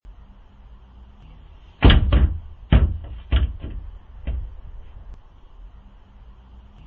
Lion Bounding Into Trailer Bouton sonore